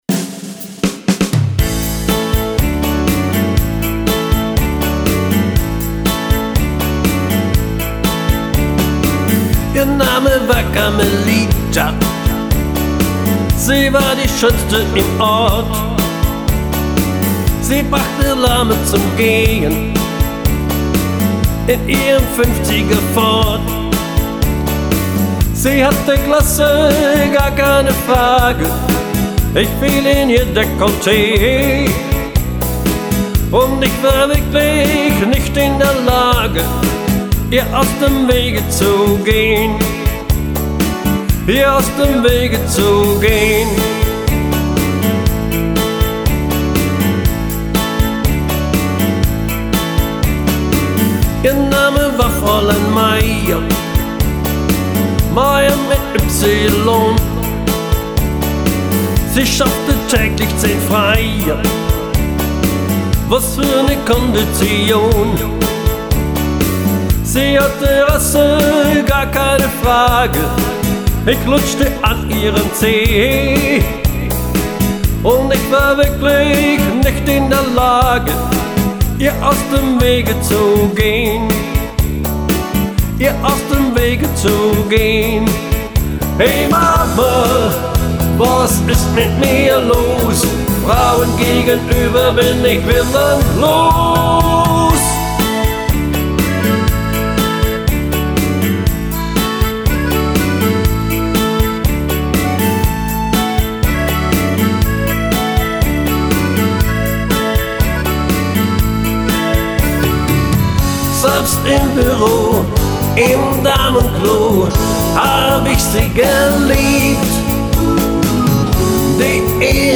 >Top Alleinunterhalter mit Livemusik<br
Instrumente Keyboard, E-Piano, Gibson Gitarre.
Gesang - auch mehrstimmig.